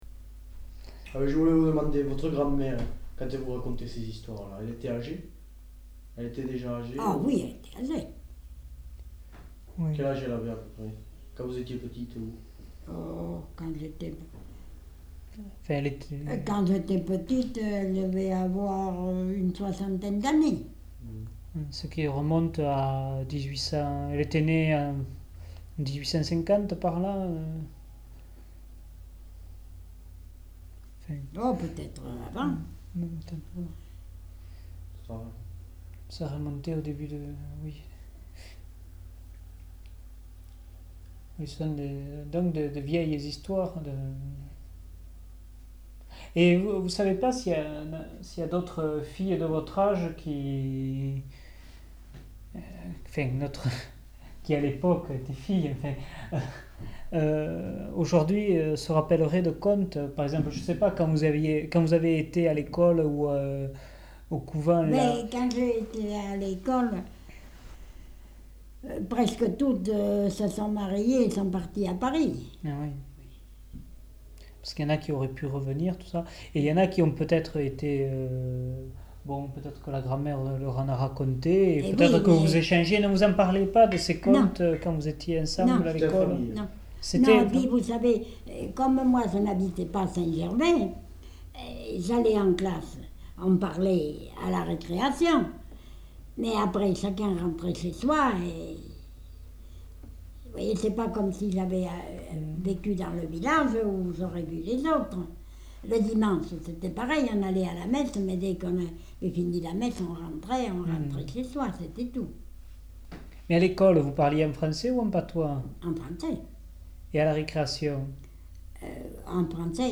Genre : récit de vie